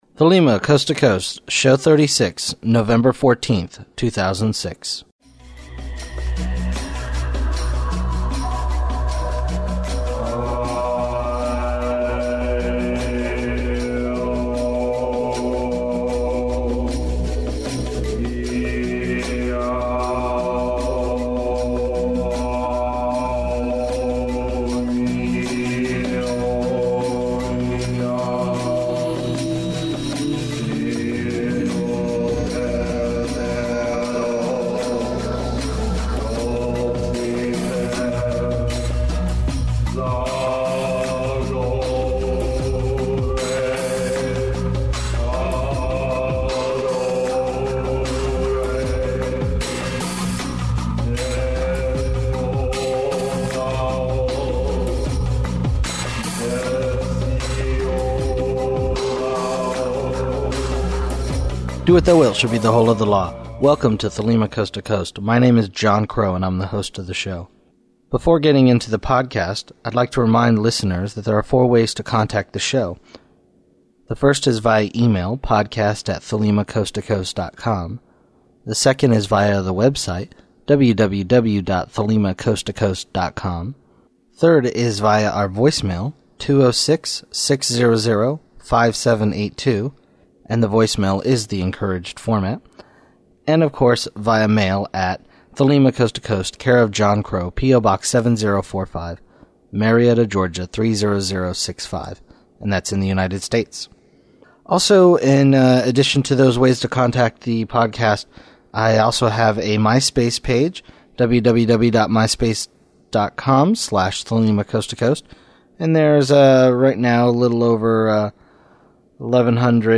Opening music
Interview